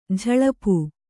♪ jhaḷapu